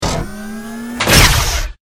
battlesuit_smalllaser.ogg